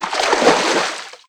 MISC Water, Splash 07.wav